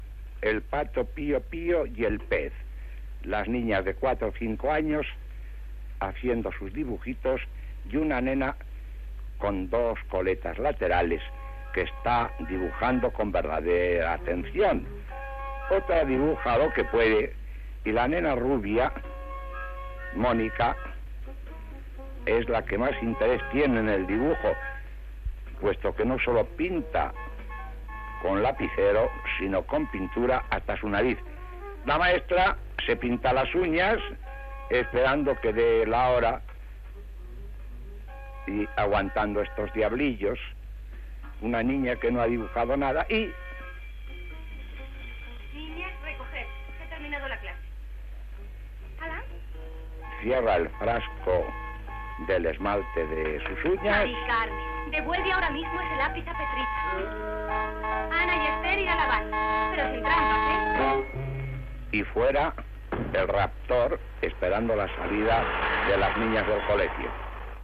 Retransmisión de una película
Transmissió de la descripció d'una pel·lícula en la qual unes nenes dibuixen i pinten